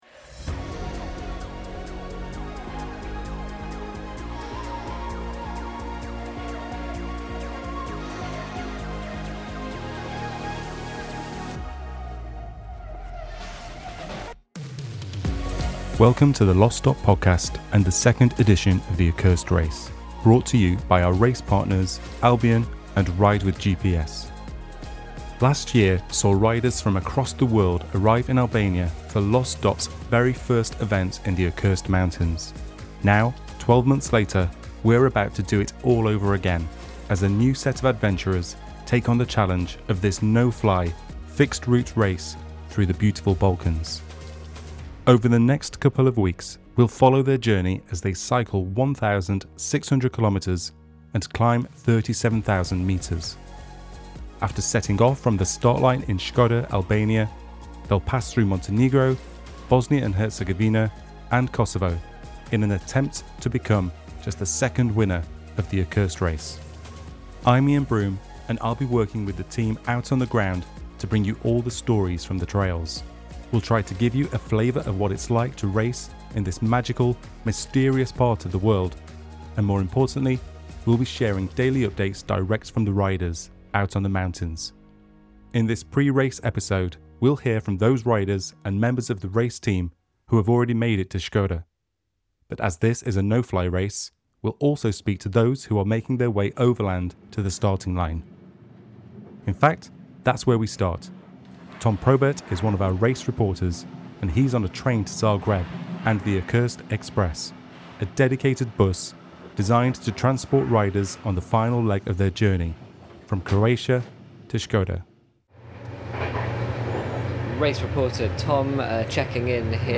the Accursed Express bus, filled with riders, volunteers, and media team members, grows closer to the Albanian Alps
interviews its passengers